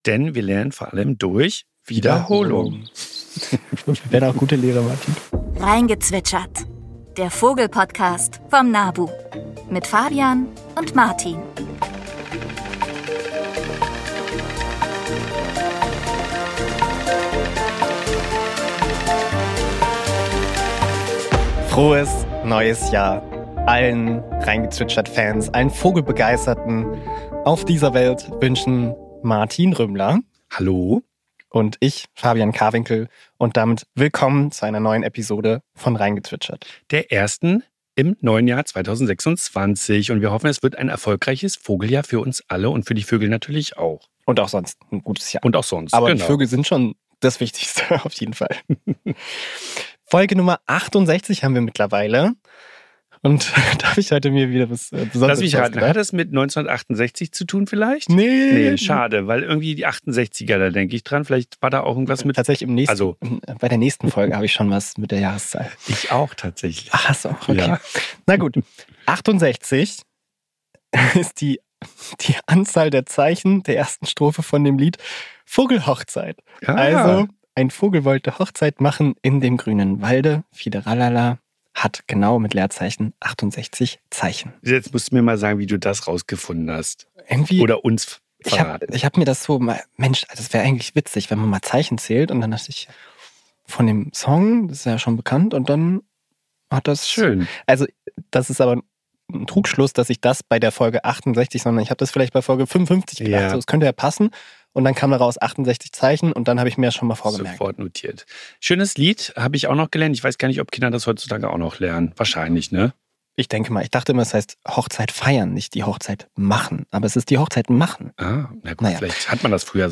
Winter ist keineswegs still: Auch jetzt zwitschert und ruft es überall.